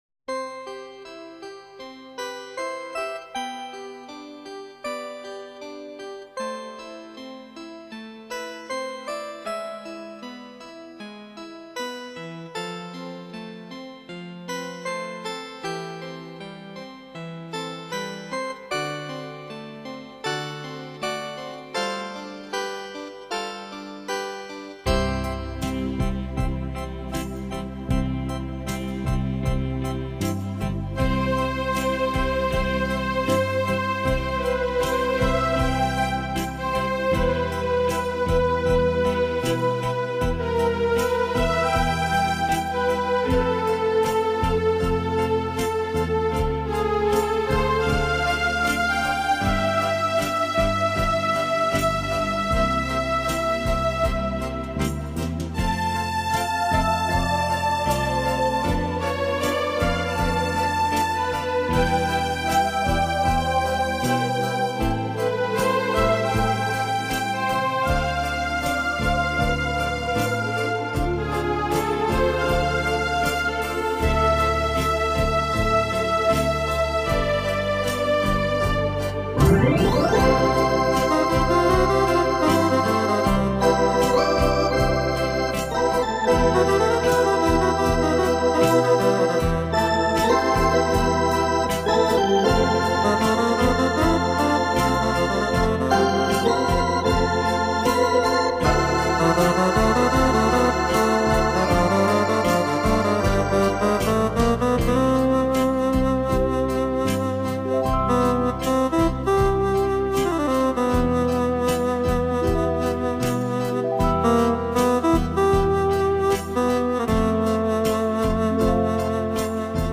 Easy Listening, Instrumental